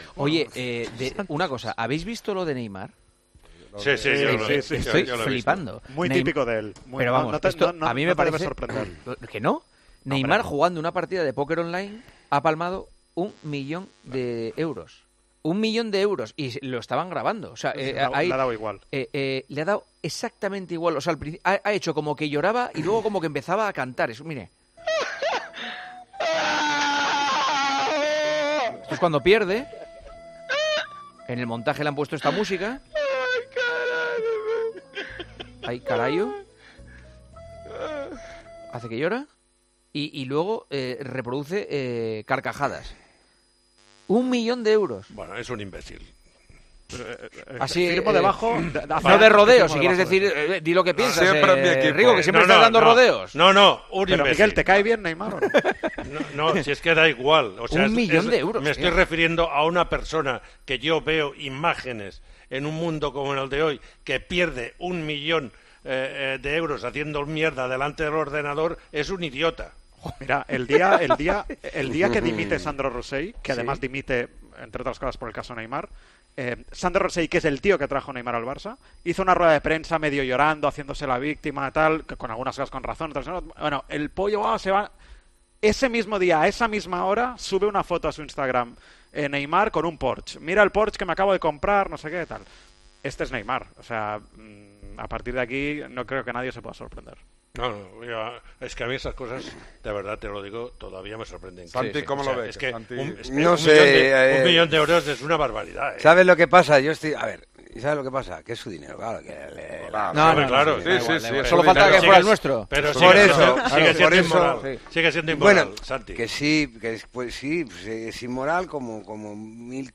Los comentaristas de El Partidazo de COPE comentaron entre el enfado y la resignación la actitud de Neymar en un 'streaming', en el que acaba perdiendo una gran cantidad de dinero.